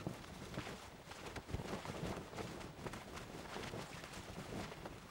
cloth_sail15.L.wav